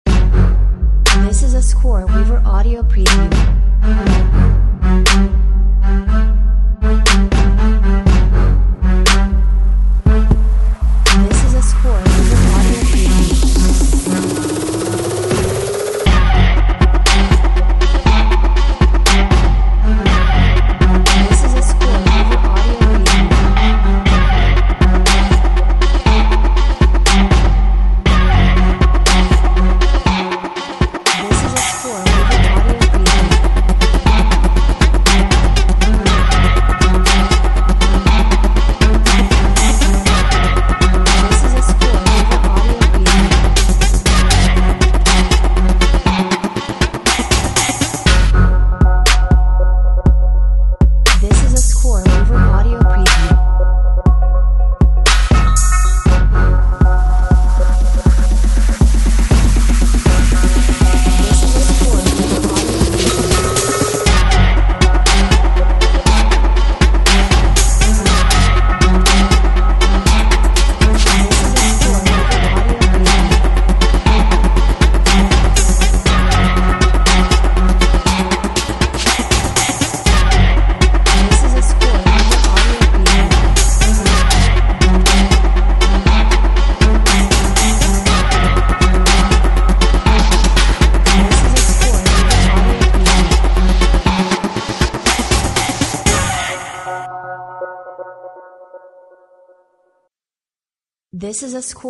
Clever Trap Step with orchestral elements!